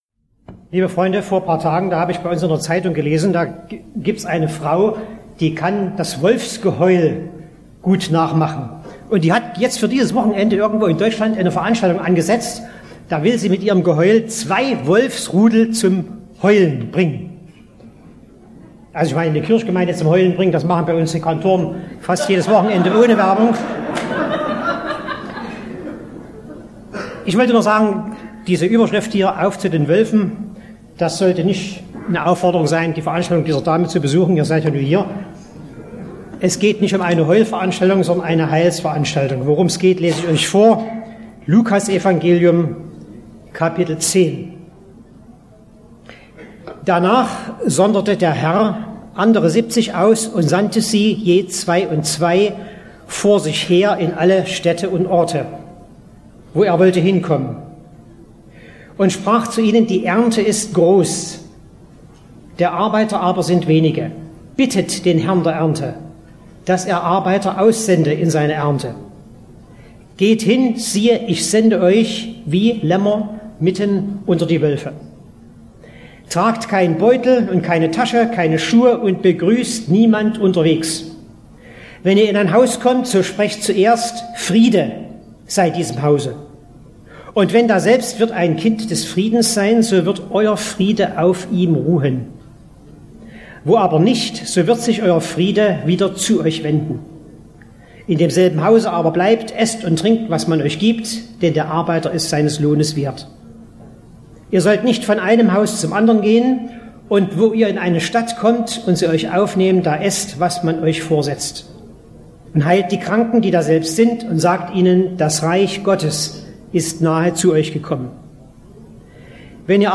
Predigt Audio Lk 10,1-12 Auf zu den Wölfen - 12. Jugendkonferenz für Weltmission